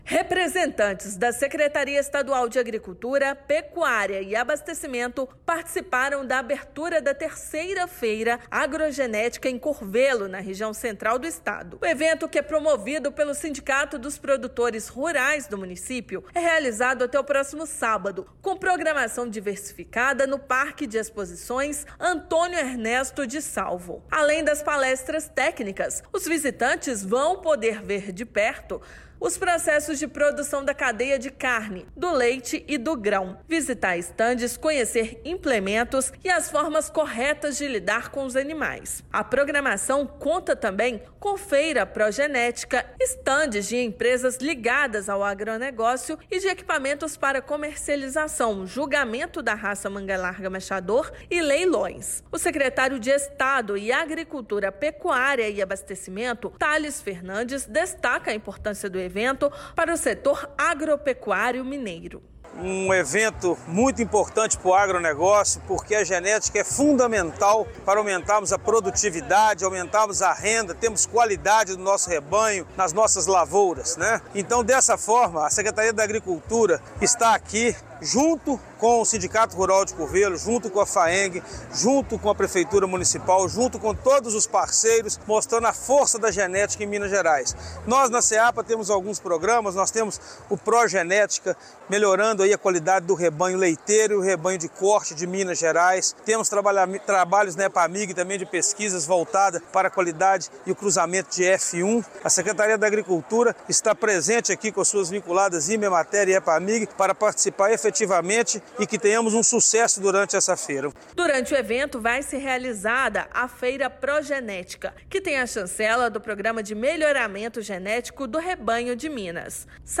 Evento é uma realização do Sindicato dos Produtores Rurais e segue até sábado (22/10) no Parque de Exposições do município. Ouça matéria de rádio.